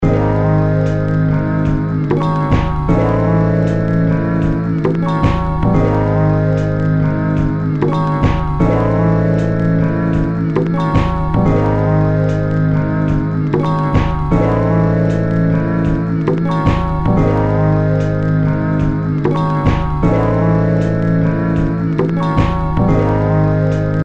Just a little loop